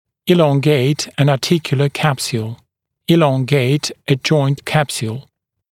[‘iːlɔŋgeɪt ən ɑː’tɪkjulə (ʤɔɪnt) ‘kæpsjuːl]